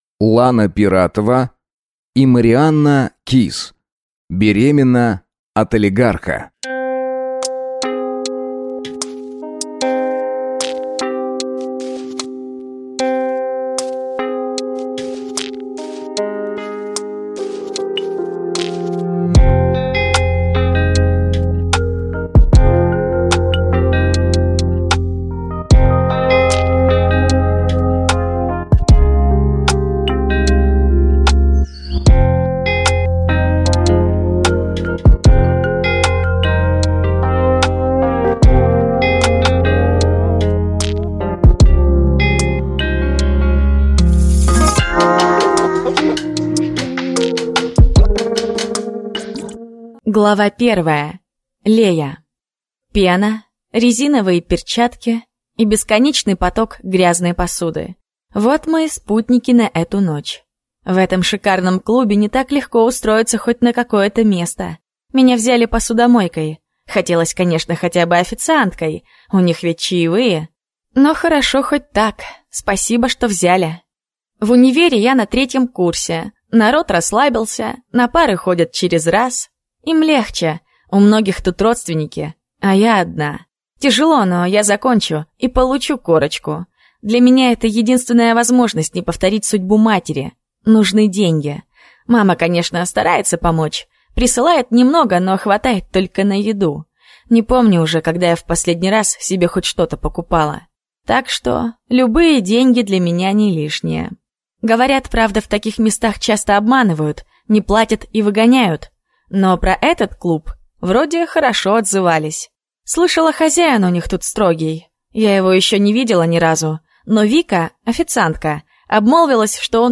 Аудиокнига Беременна от олигарха | Библиотека аудиокниг